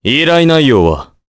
Crow-vox-get_jp_b.wav